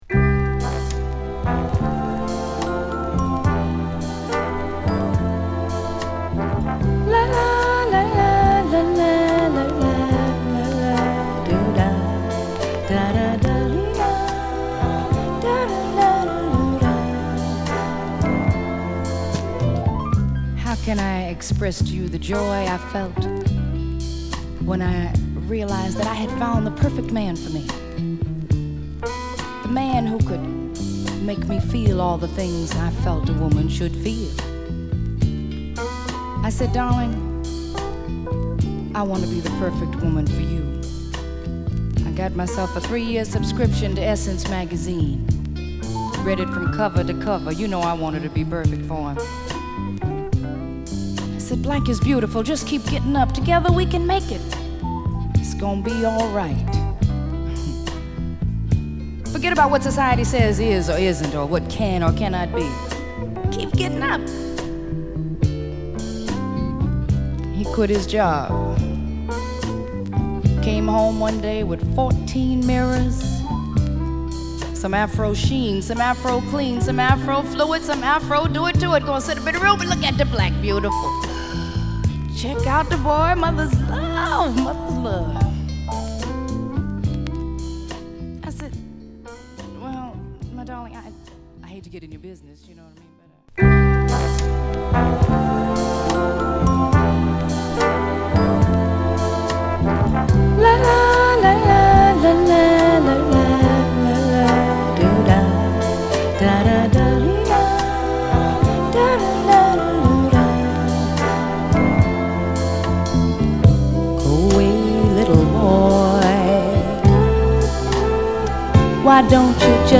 メロウなギターと控えたリズムでのトラックに語りを中心にシットリとしたヴォーカルを乗せる！